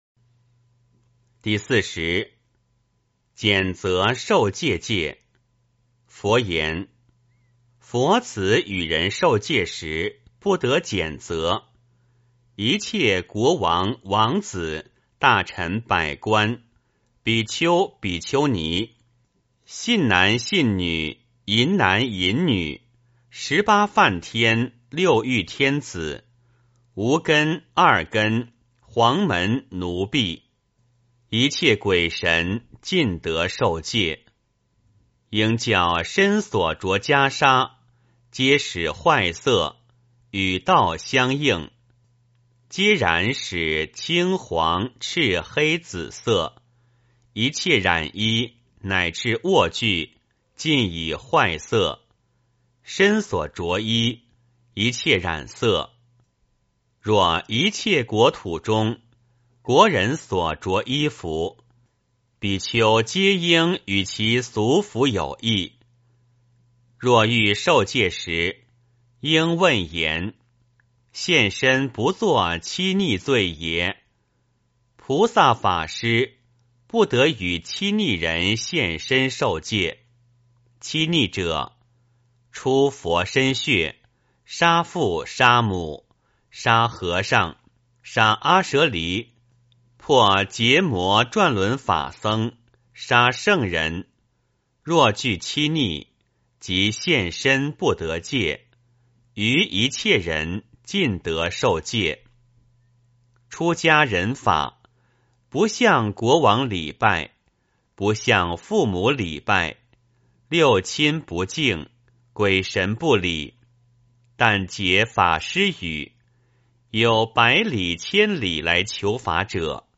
梵网经-四十八轻戒40 诵经 梵网经-四十八轻戒40--未知 点我： 标签: 佛音 诵经 佛教音乐 返回列表 上一篇： 梵网经-四十八轻戒 下一篇： 永嘉证道歌 相关文章 金刚经-大乘正宗分第三 金刚经-大乘正宗分第三--未知...